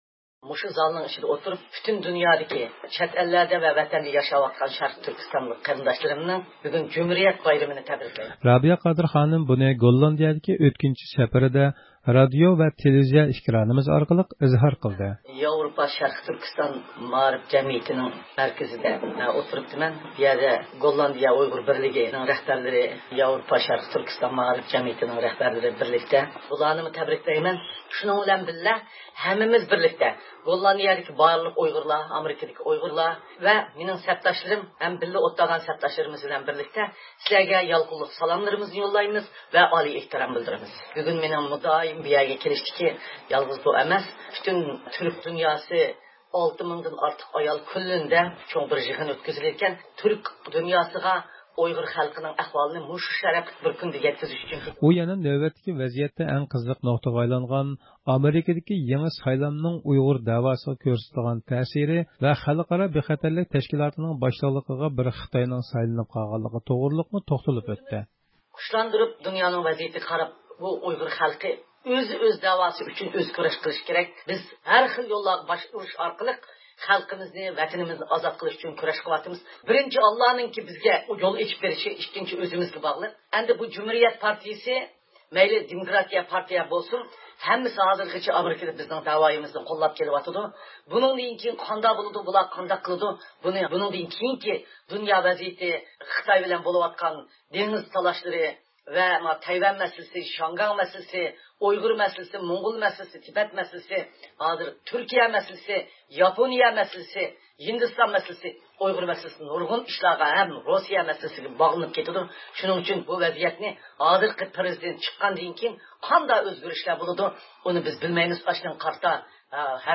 ئۇيغۇر مىللىي ھەرىكىتى يېتەكچىسى، دۇنيا ئۇيغۇر قۇرۇلتىيى رەئىسى رابىيە قادىر خانىم پۈتۈن دۇنيادىكى ئۇيغۇرلارنىڭ جۇمھۇرىيەت كۈنىنى تەبرىكلىدى.
رابىيە قادىر خانىم گوللاندىيەدىكى ئۆتكۈنچى زىيارىتىدە گوللاندىيىدىكى ئۇيغۇر تەشكىلاتلىرى رەھبەرلىرى بىلەن ئۇچراشقاندا قىلغان تەبرىك سۆزلىرىنى رادىئو ۋە تېلېۋىزىيە ئېكرانى ئارقىلىق ئىزھار قىلدى.